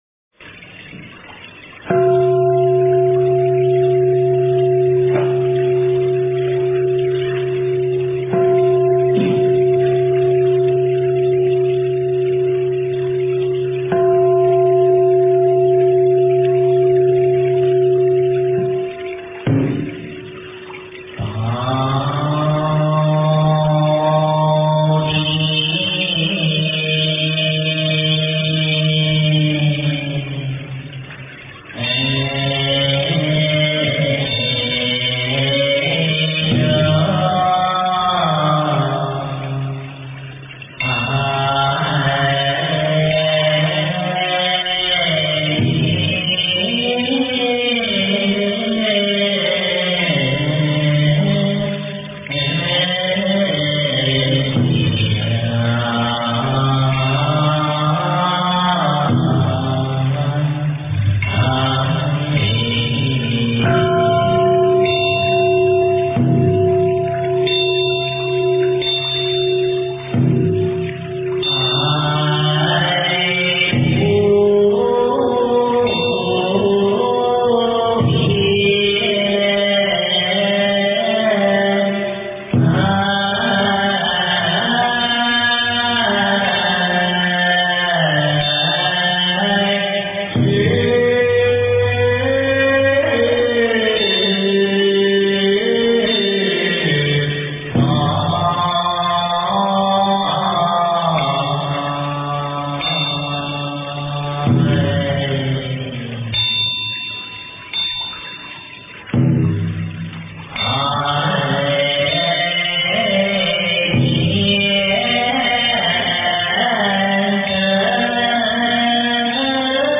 经忏
佛音 经忏 佛教音乐 返回列表 上一篇： 普佛(代晚课